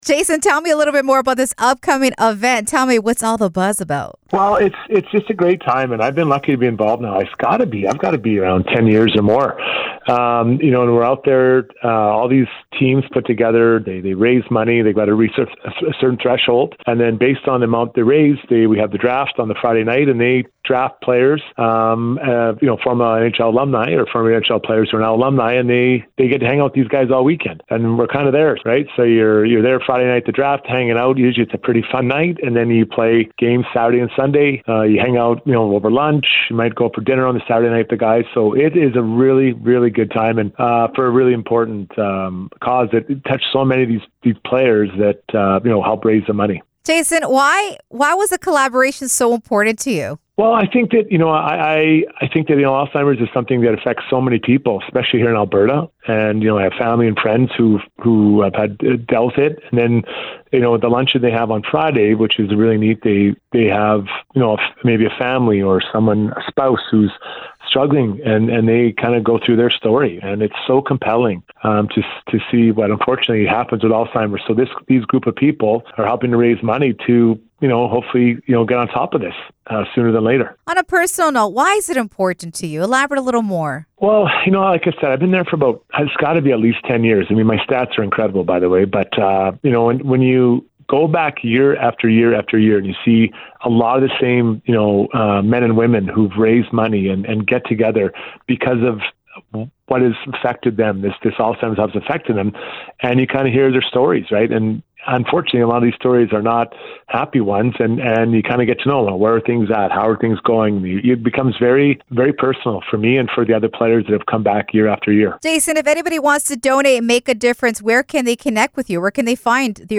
Exclusive interview with Oiler’s Alumni | Hockey Star Jason Strudwick: